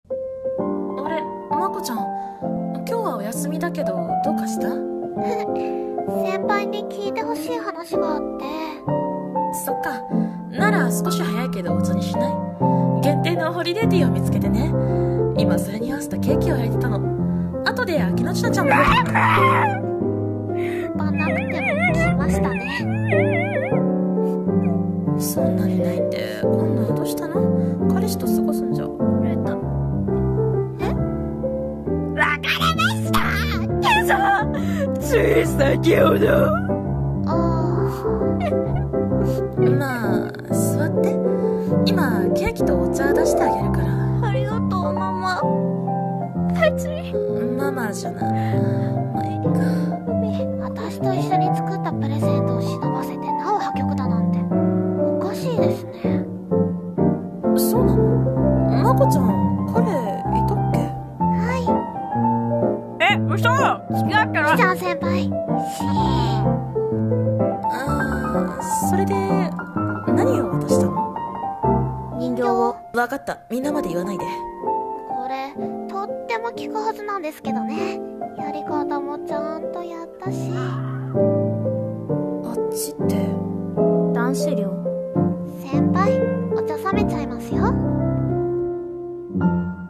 声劇